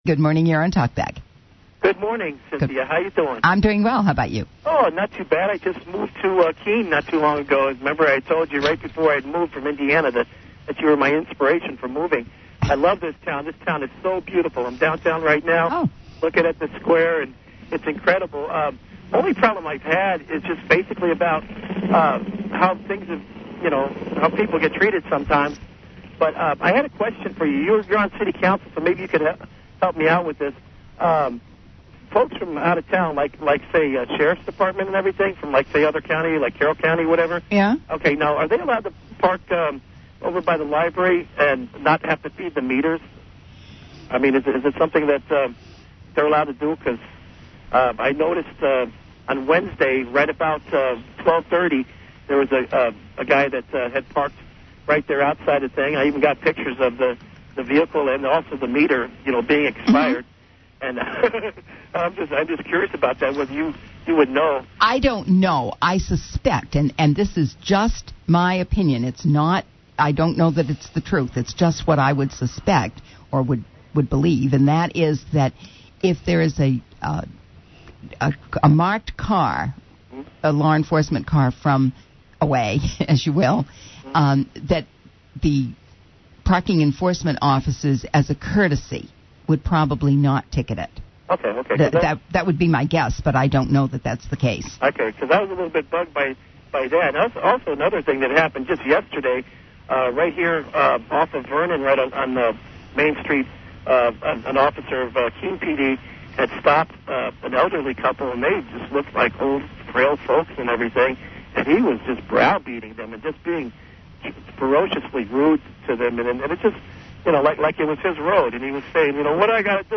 Pro-Liberty Callers to WKBK’s “Talkback” 2009-05-02
You’ll also hear from the old-guard statist contingent.